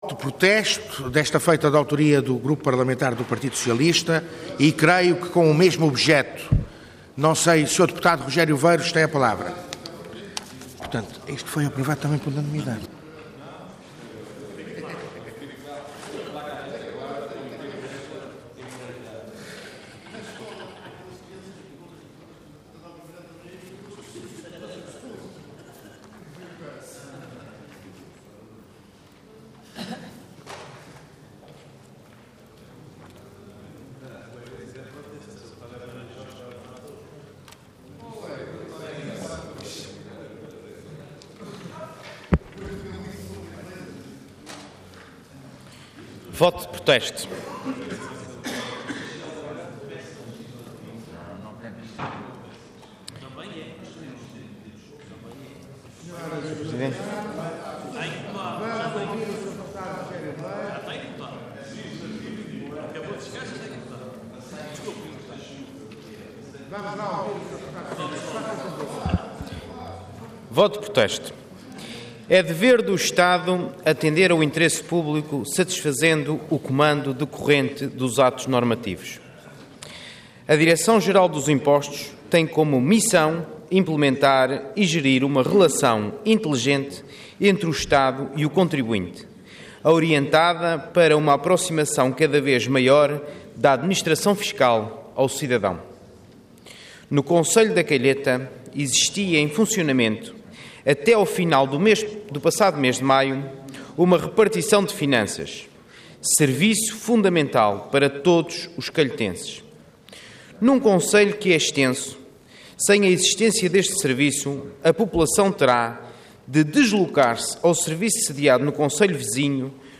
Intervenção Voto de Protesto Orador Rogério Veiros Cargo Deputado Entidade PS